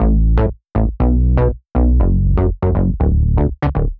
Index of /musicradar/french-house-chillout-samples/120bpm/Instruments
FHC_SulsaBass_120-A.wav